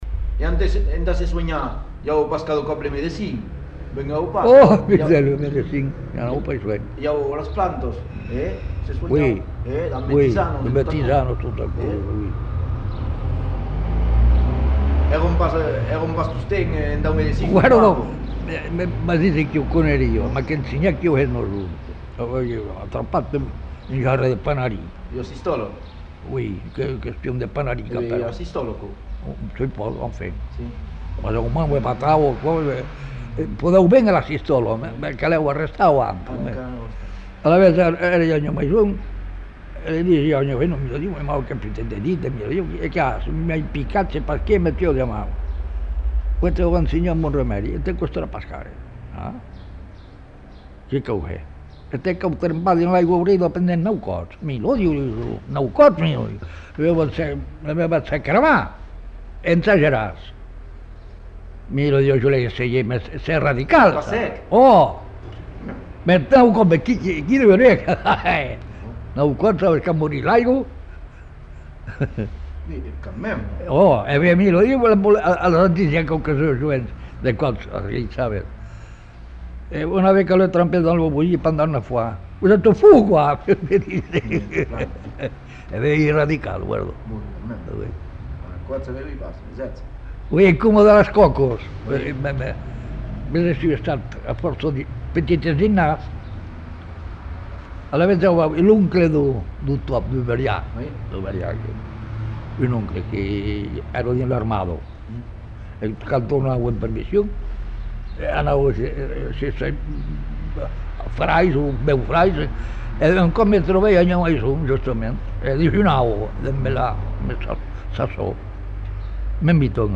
Aire culturelle : Savès
Lieu : Lombez
Genre : témoignage thématique